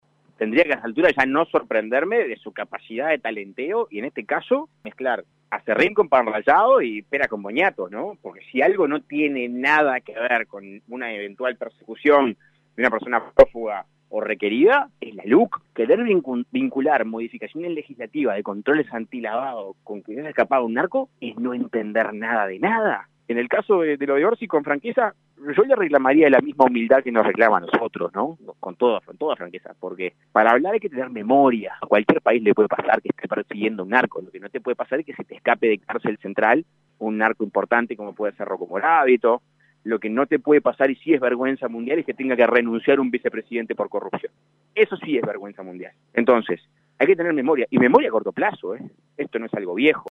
Escuche las declaraciones del dirigente colorado Andrés Ojeda